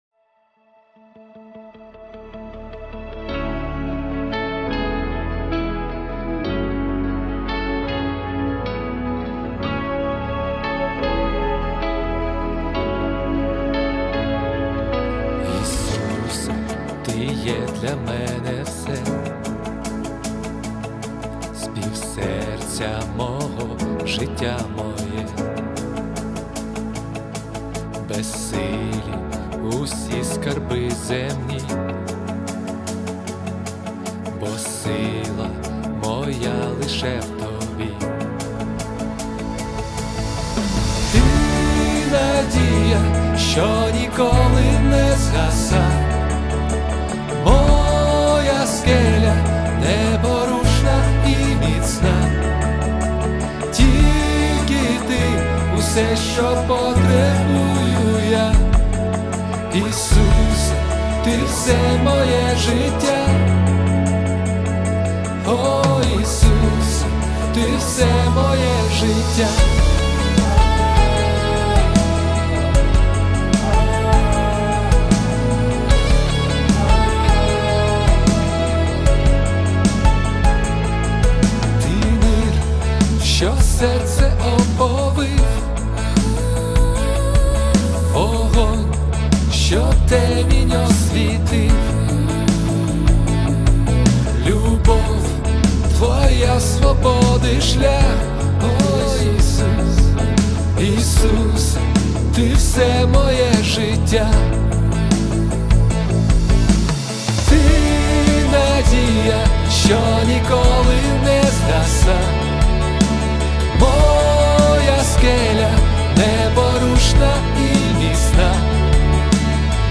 38 просмотров 82 прослушивания 1 скачиваний BPM: 76